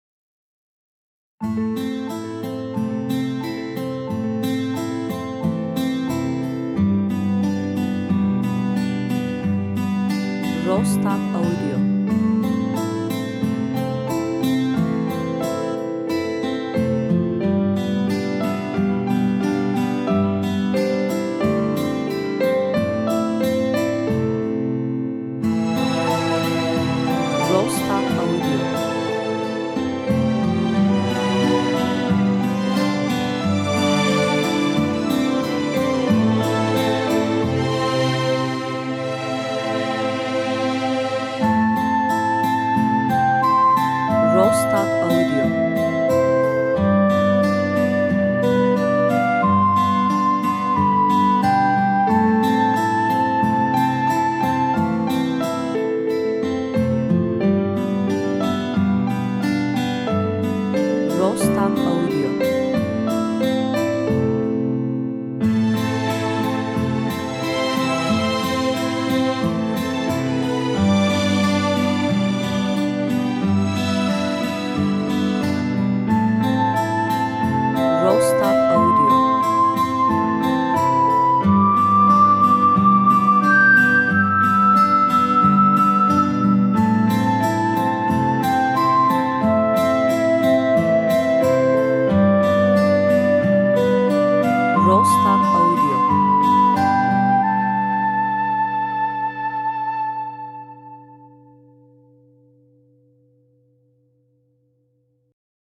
enstrümantal ses müzik music epik epic